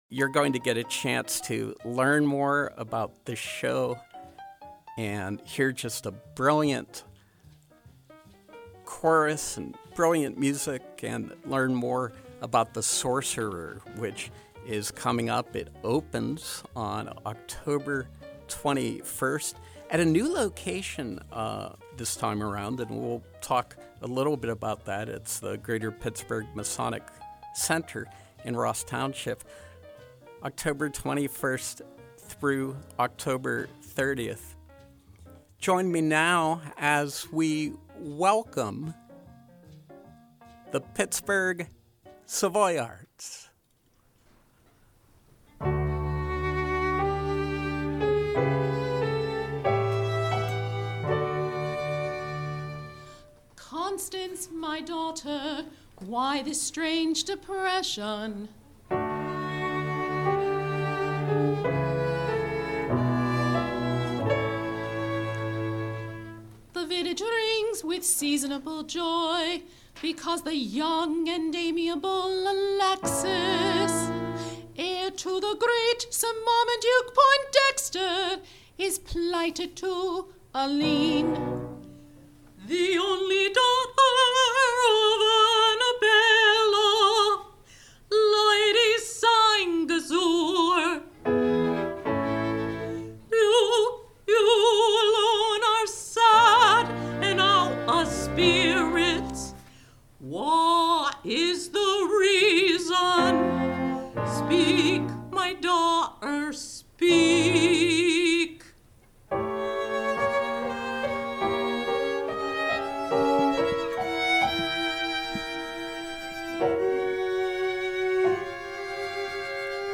Live Music: The Sorcerer, Pittsburgh Savoyards
From 10/08/2022: The Pittsburgh Savoyards with a live preview of musical numbers from The Sorcerer (October 21-30 at Greater Pittsburgh Masonic Center, Ross Township, PA).